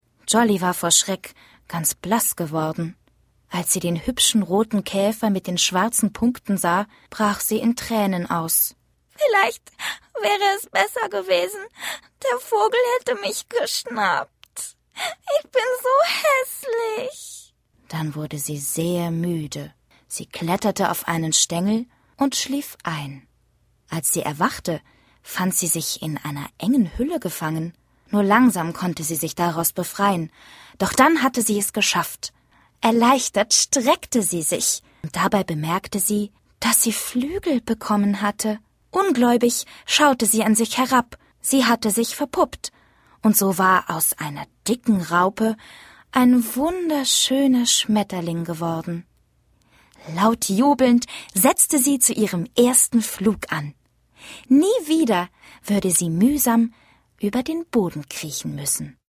Sprechprobe: Sonstiges (Muttersprache):
german female voice over artist